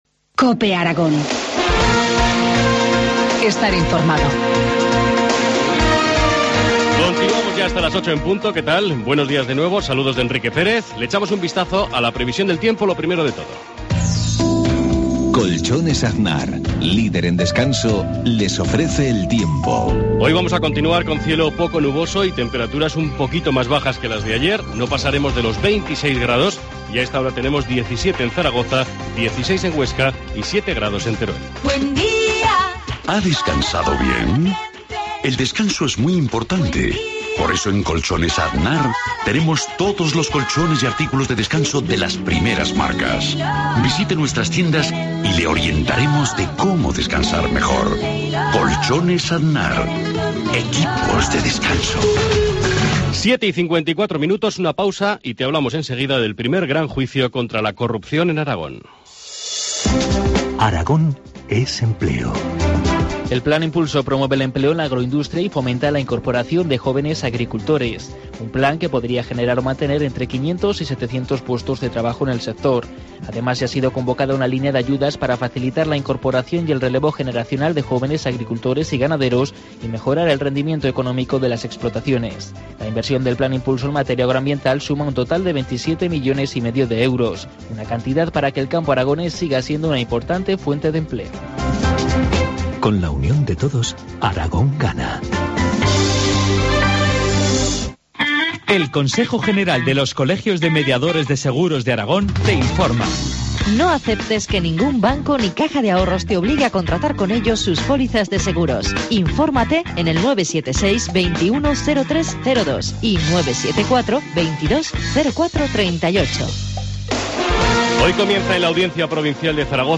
Informativo matinal, lunes 21 de octubre, 7.53 horas